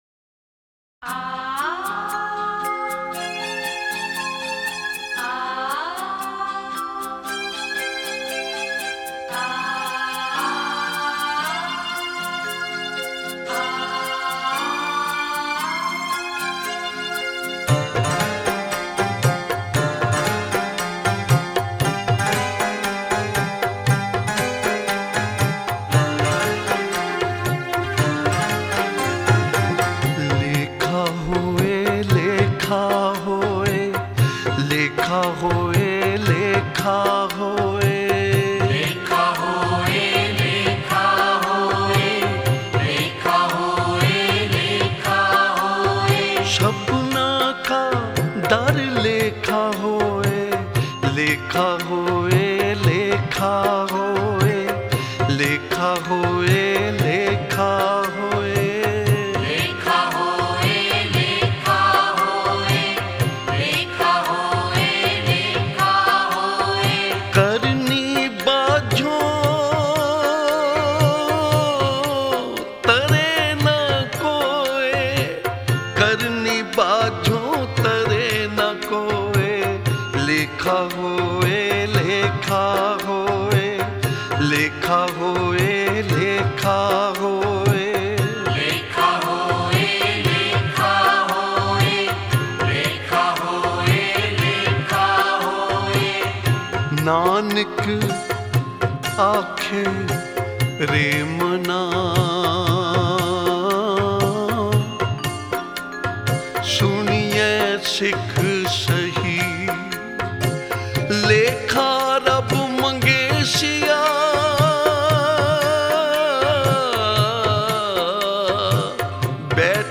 Genre: Shabad Gurbani Kirtan Album Info